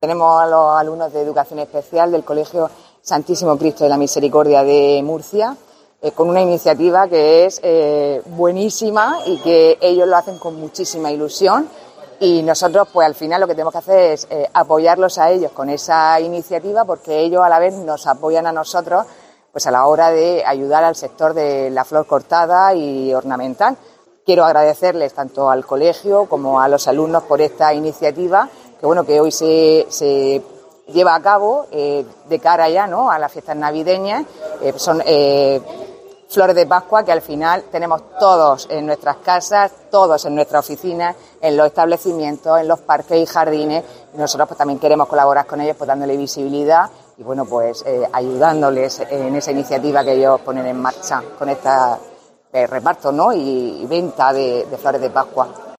Sara Rubira, consejera de Agua, Agricultura, Ganadería y Pesca
Así lo expresó durante la tradicional jornada de venta de flor de pascua llevada a cabo por los alumnos del Colegio de Educación Especial Santísimo Cristo de la Misericordia de Murcia en las instalaciones de la Consejería.